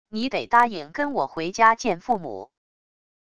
你得答应跟我回家见父母wav音频生成系统WAV Audio Player